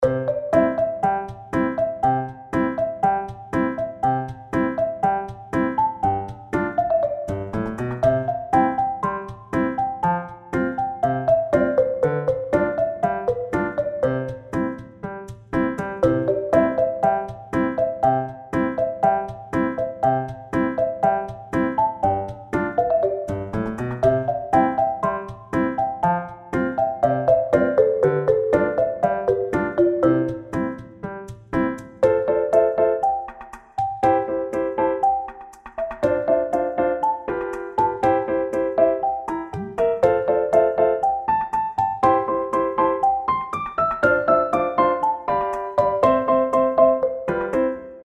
ファンタジー系フリーBGM｜ゲーム・動画・TRPGなどに！
かわいいシンセの音をみつけたので使ってみたかった。